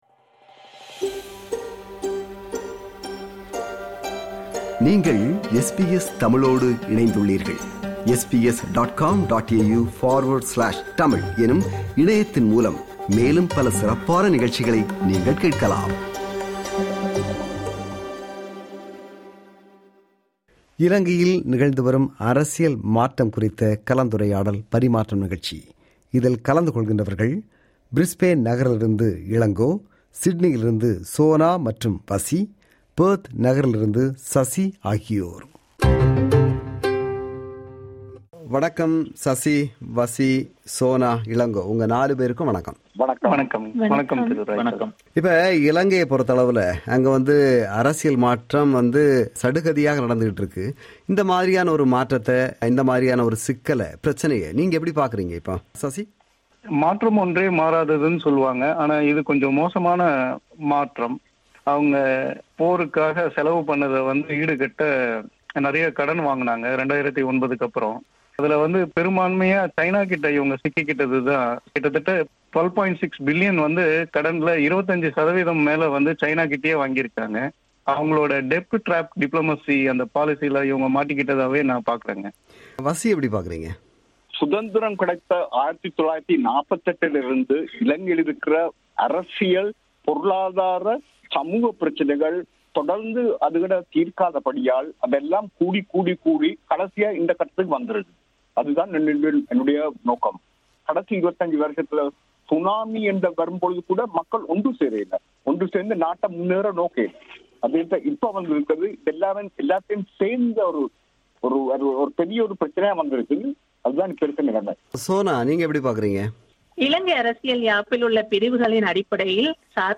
Unprecedented economic crisis looks to have finally toppled President Rajapaksa. The panel discuss if they see the crisis an opportunity that speeds up the process of reconciliation among ethnic communities and finding political solution to the Tamil political problem.